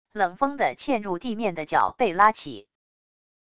冷锋的嵌入地面的脚被拉起wav音频